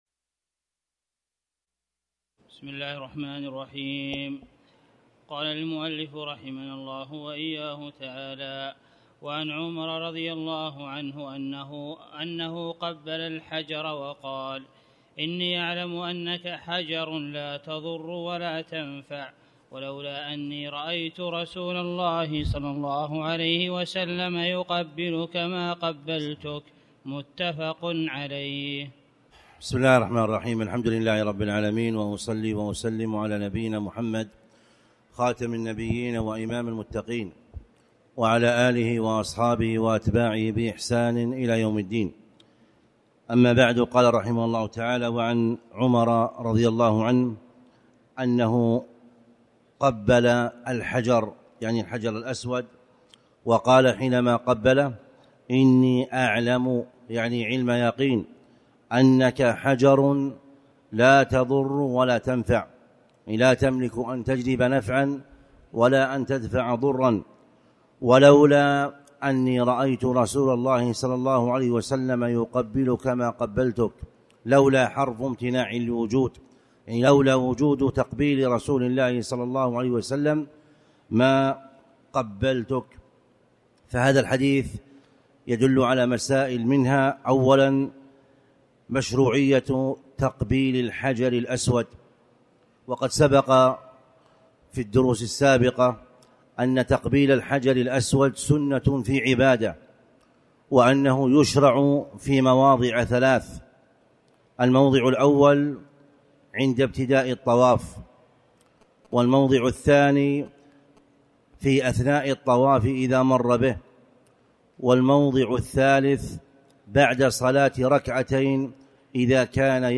تاريخ النشر ٤ ذو القعدة ١٤٣٨ هـ المكان: المسجد الحرام الشيخ